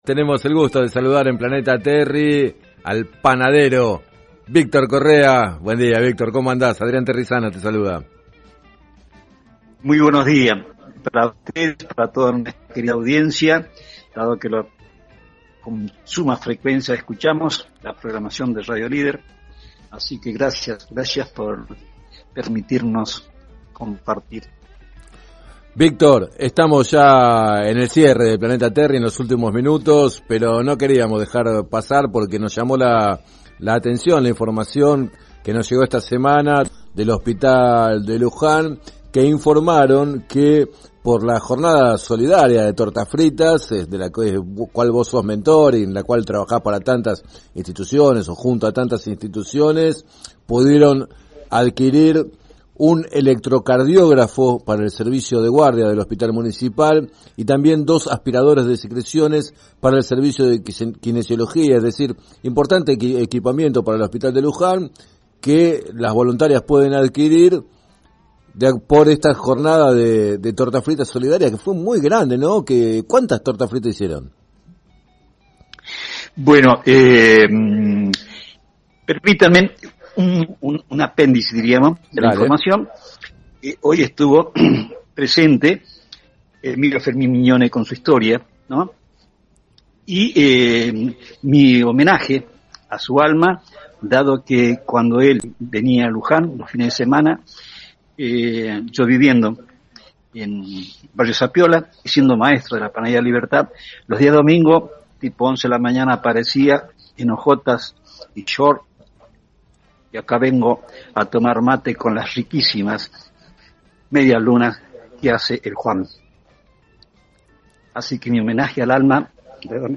En tanto, en declaraciones al programa Planeta Terri de FM Líder 97.7